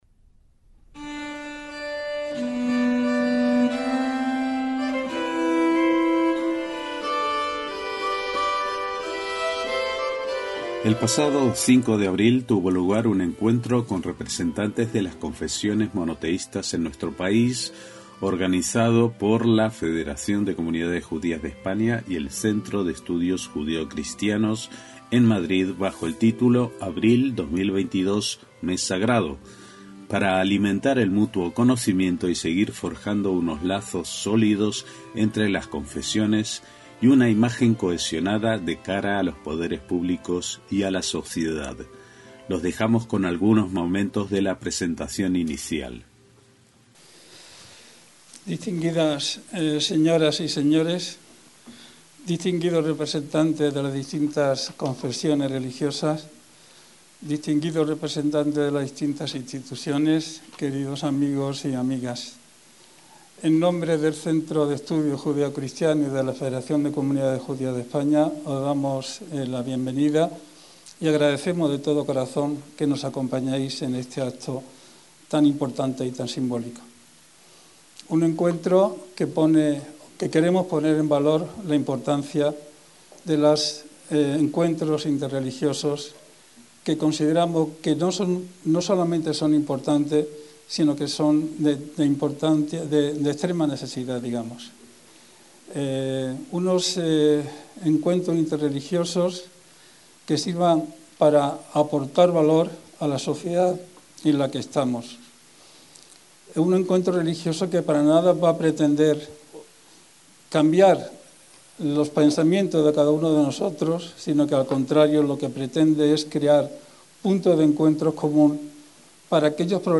Encuentro interreligioso: Abril 2022, mes sagrado